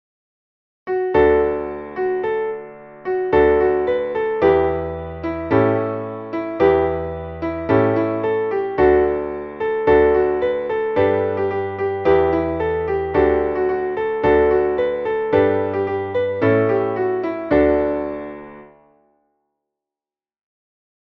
Traditionelles Volkslied / Scherzlied